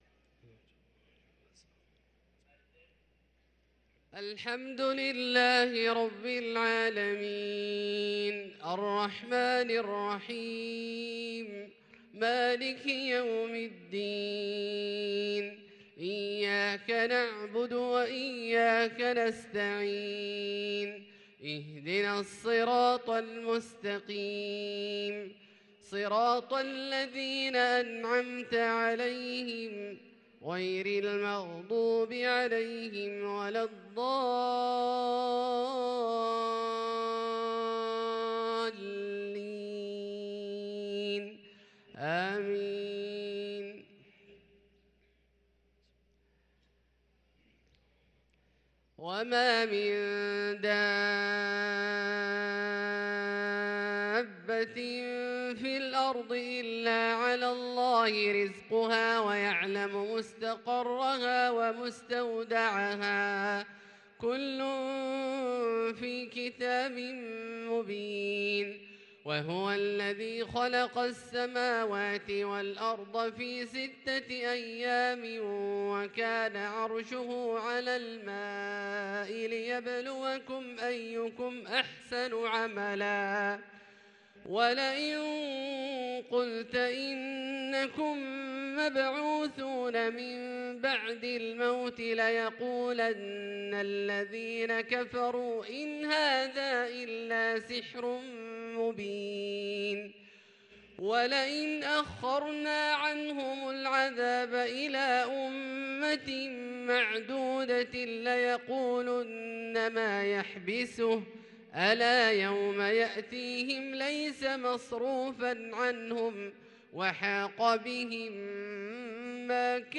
صلاة العشاء للقارئ عبدالله الجهني 14 جمادي الآخر 1444 هـ